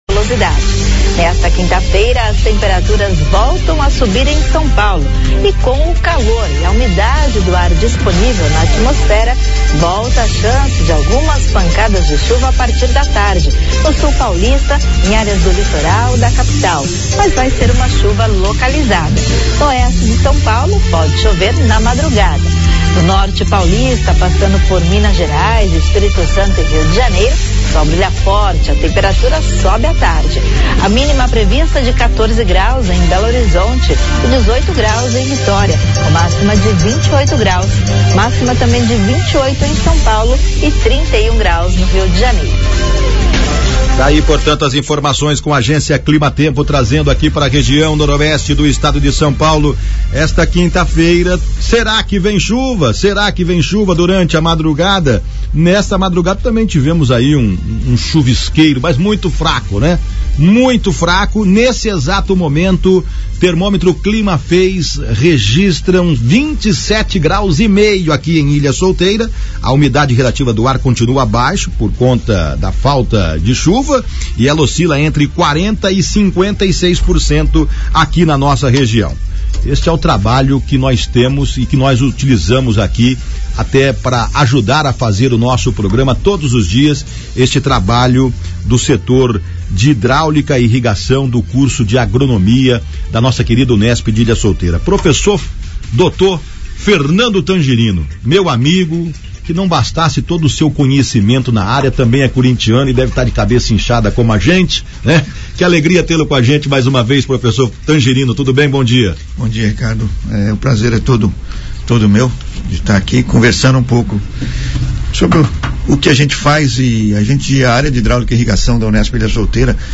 A entrevista iniciou-se com uma apresentação sobre a Área de Hidráulica e Irrigação , a UNESP Ilha Solteira e a importância da agricultura como um todo na sociedade, seguindo com um pouco de história sobre o surgimento e funcionamento da Rede Agrometeorológica do Noroeste Paulista .